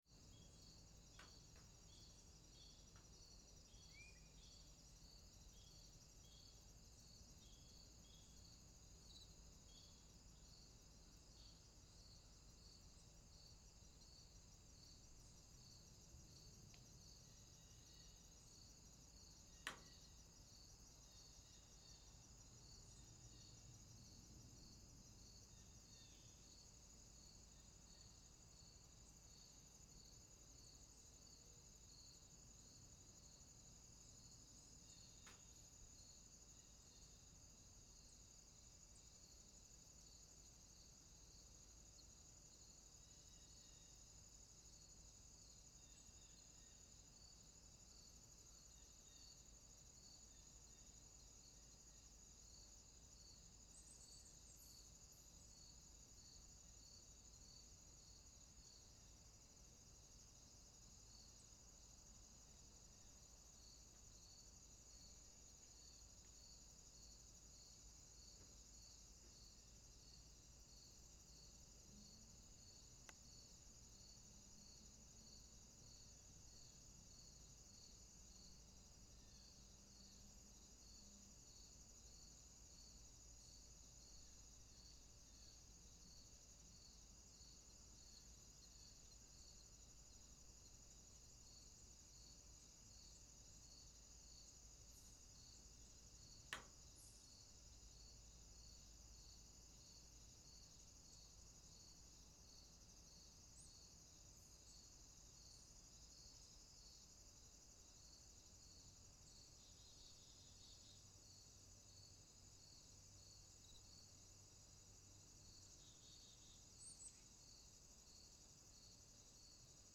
Ambient_WarmAfternoonOutdoors.wav